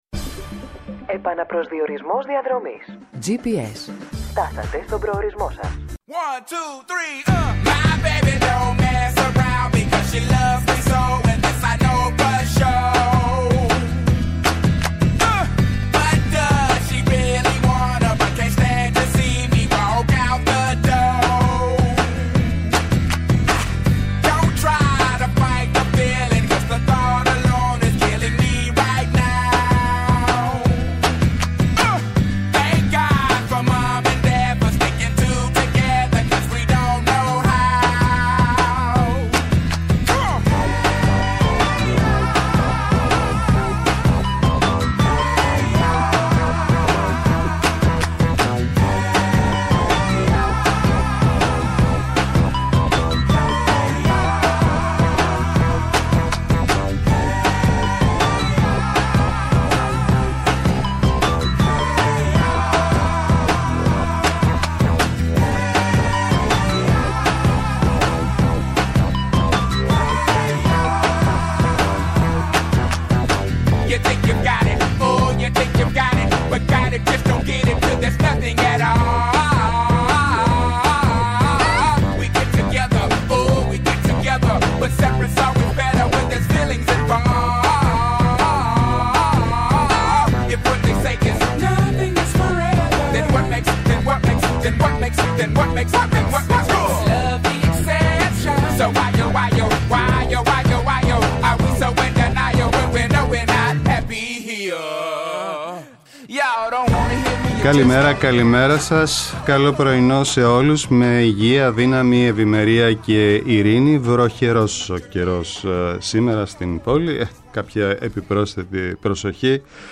-O Γιάννης Πανούσης, Καθηγητής Εγκληματολογίας του Πανεπιστημίου Αθηνών-πρώην υπουργός Δικαιοσύνης
Eνα καθημερινό ραντεβού με τον παλμό της επικαιρότητας, αναδεικνύοντας το κοινωνικό στίγμα της ημέρας και τις αγωνίες των ακροατών μέσα από αποκαλυπτικές συνεντεύξεις και πλούσιο ρεπορτάζ